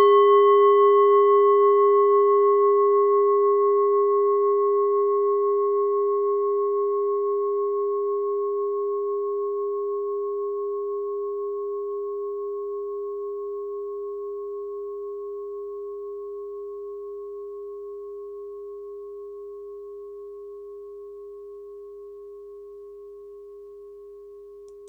Klangschale Orissa Nr.11
Klangschale-Durchmesser: 14,2cm
Sie ist neu und wurde gezielt nach altem 7-Metalle-Rezept in Handarbeit gezogen und gehämmert.
Die Pi-Frequenz kann man bei 201,06 Hz hören. Sie liegt innerhalb unserer Tonleiter nahe beim "Gis".
klangschale-orissa-11.wav